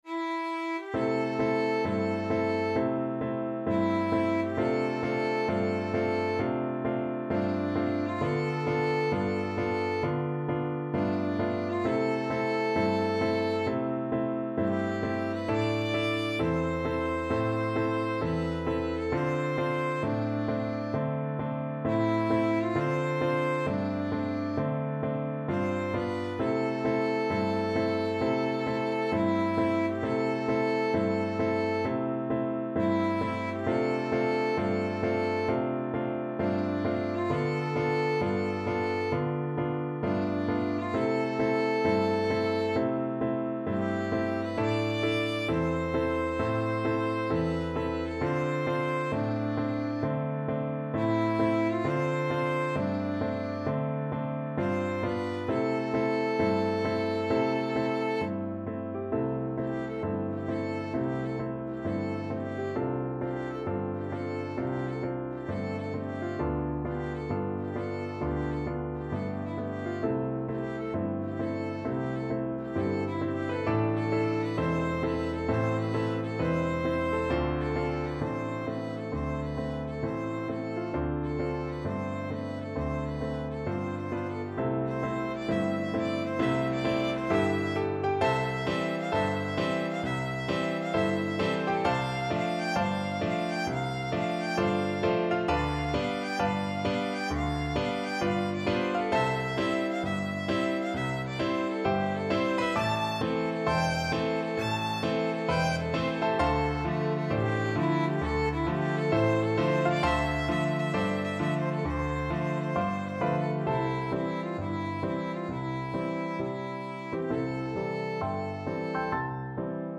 2/2 (View more 2/2 Music)
Blues Tempo (=66)
Jazz (View more Jazz Violin Music)